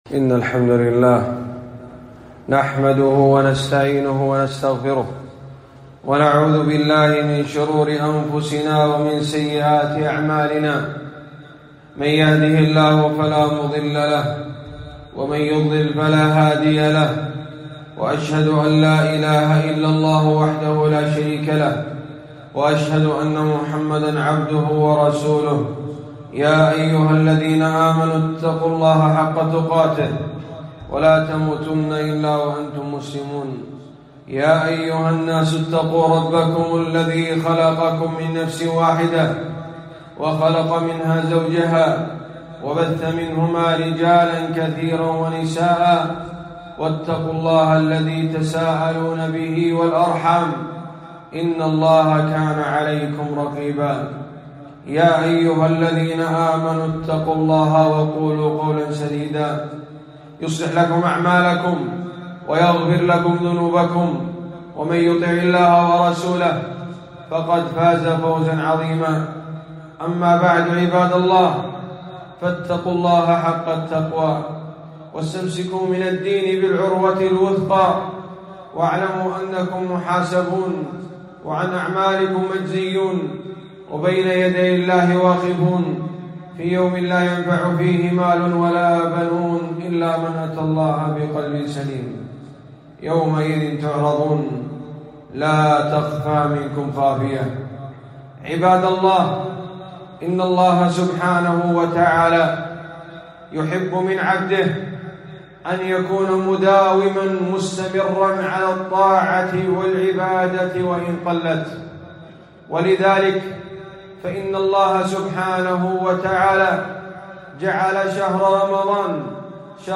خطبة - فضل الاستمرار على الطاعات